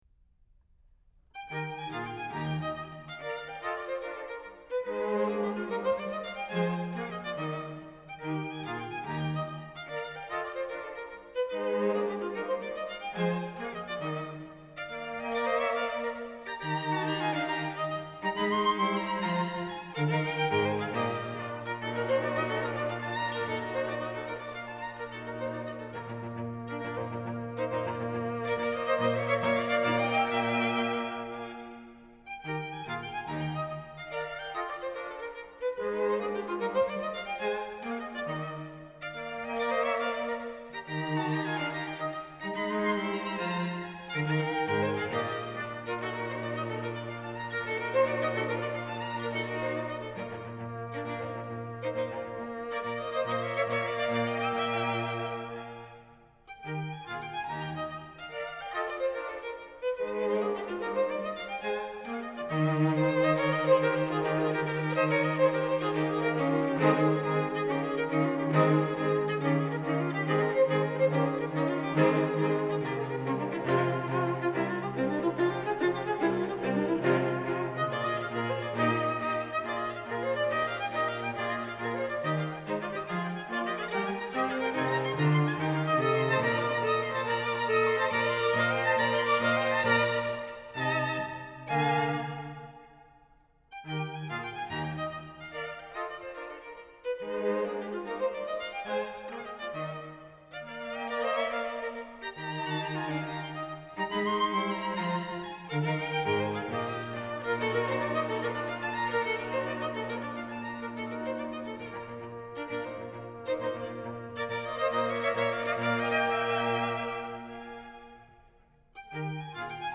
Joseph Haydn, String Quartet Op. 33 No. 2 “The Joke,” IV. Presto (1781)
haydn-quartet-op-33-no-2-iv.mp3